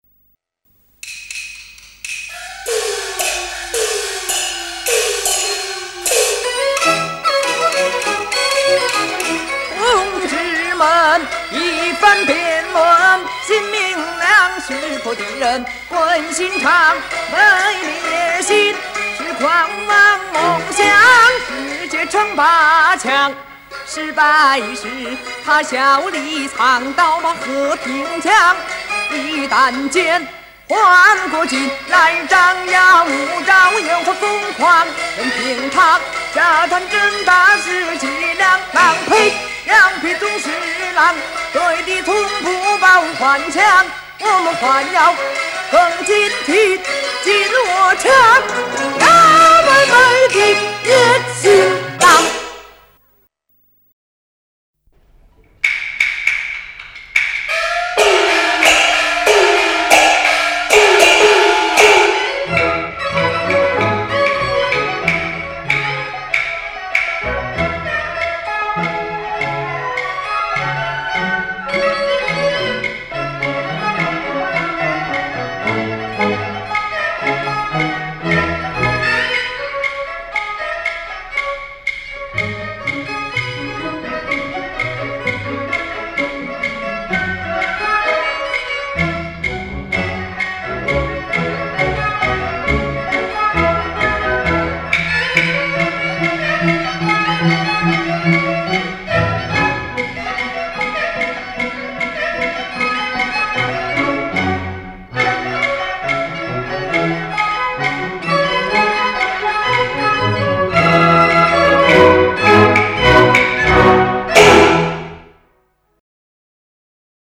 原唱+立体声伴奏